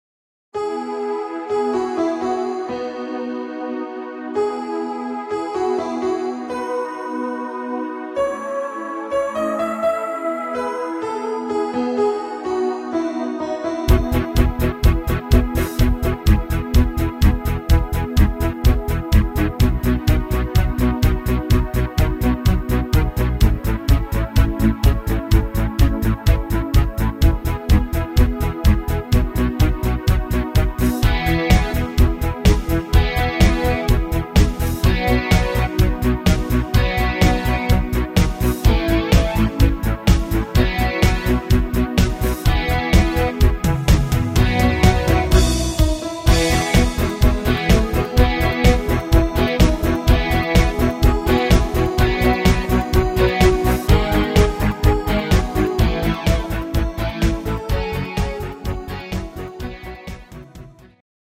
Rhythmus  Disco Beat
Art  Schlager 90er, Deutsch